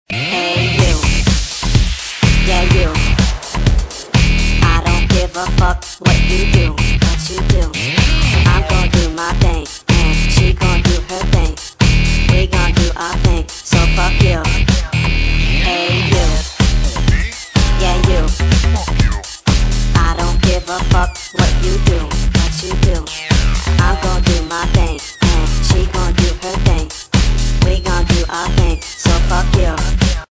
Dance & Trance
:o One of my favorite house music songs.